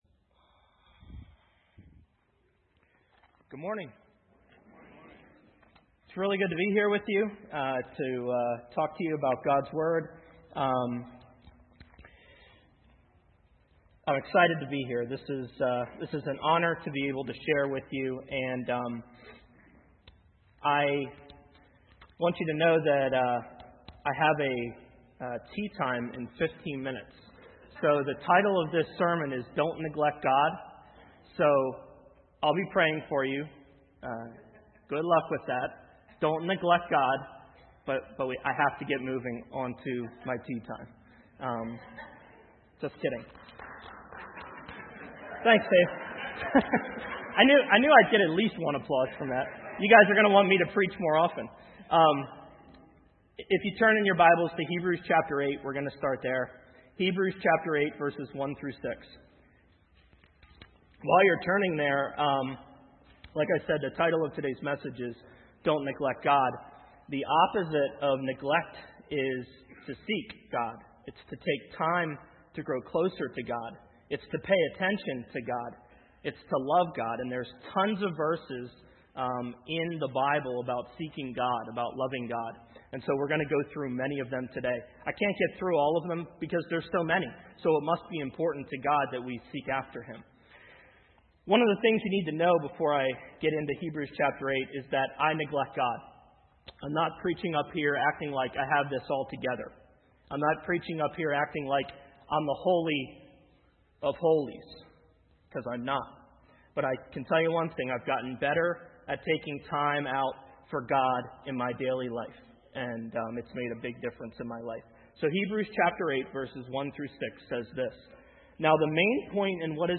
Sermons | Fairland Church